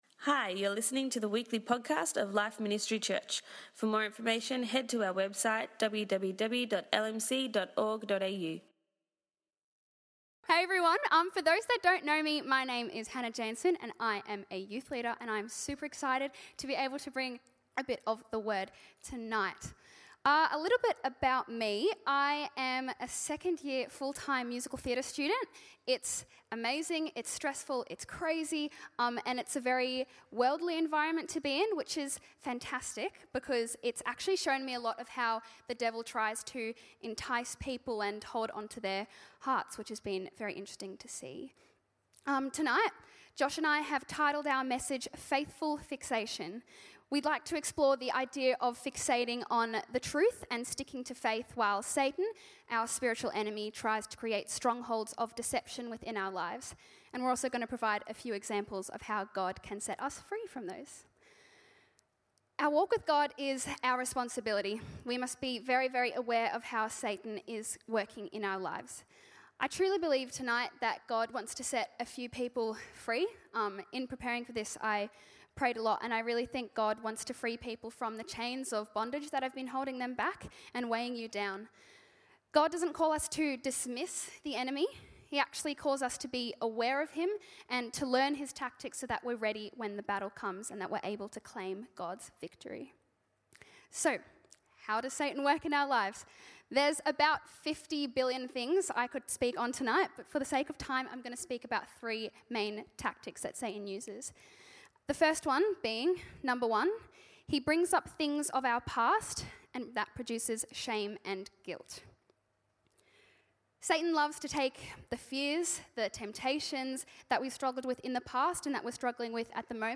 Tonight we heard from two of our Frontline Youth leaders as they shared a message titled Faithful Fixation.